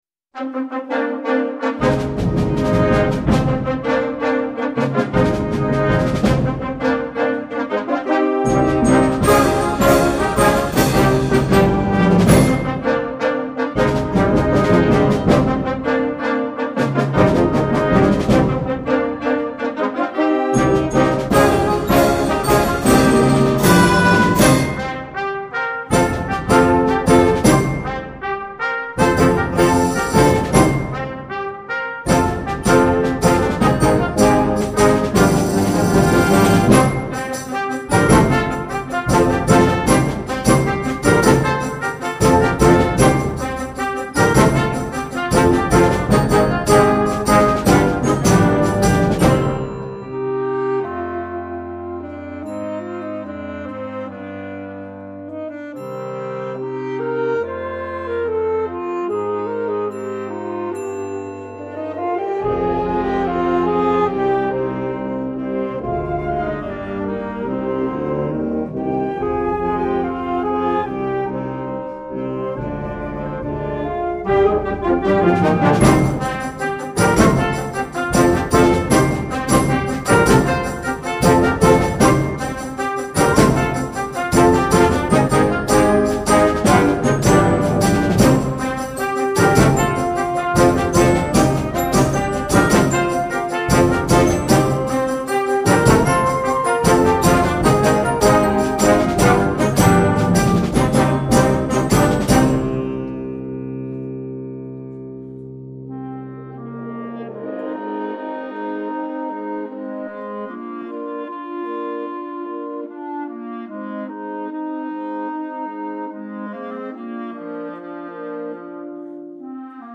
• Concert Band